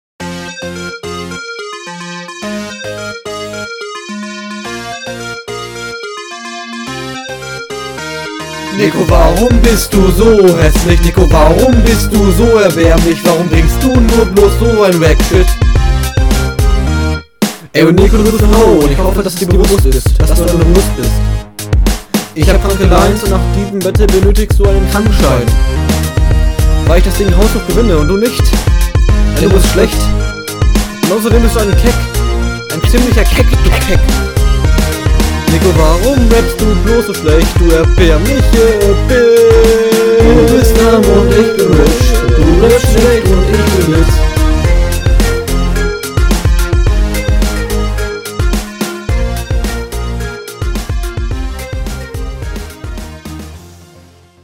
Der Beat ist super. Deine Doubles sind leider wieder teilweise nicht synchron.